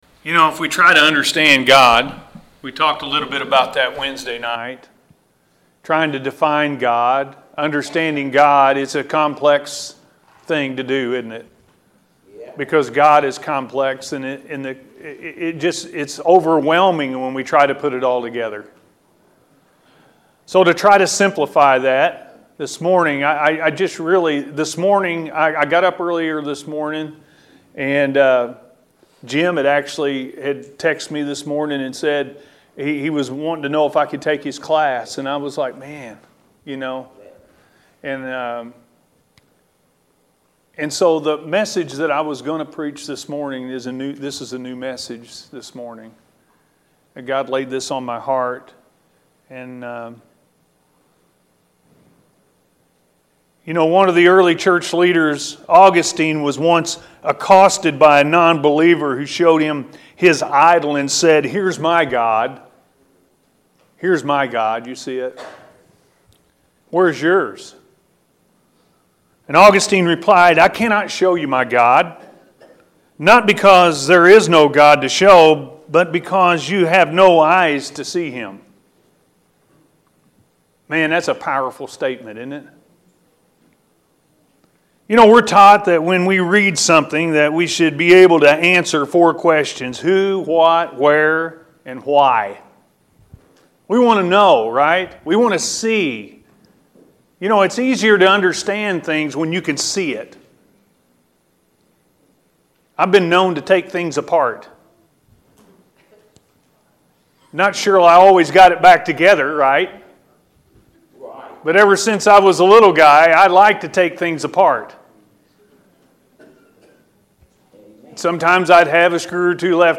God Is Complex-A.M. Service